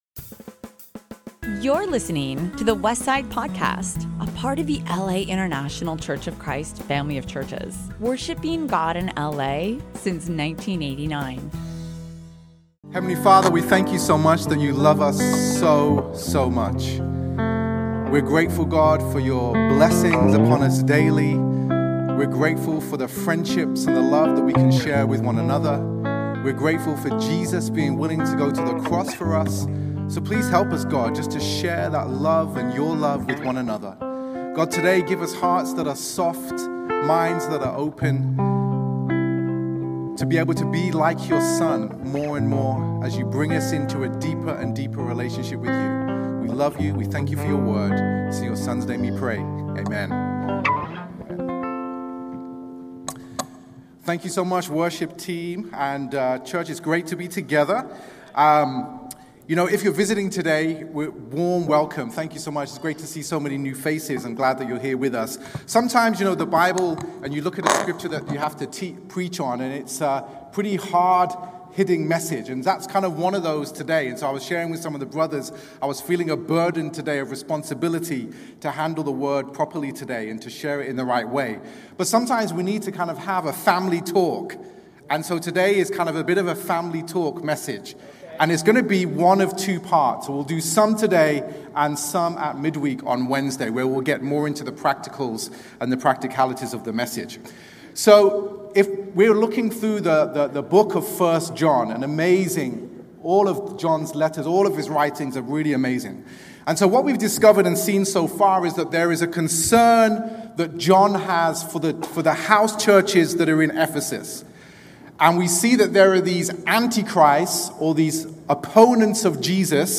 Past Sermons | The Westside Church - Los Angeles, CA